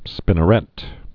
(spĭnə-rĕt)